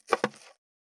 537切る,包丁,厨房,台所,野菜切る,咀嚼音,
効果音厨房/台所/レストラン/kitchen食器食材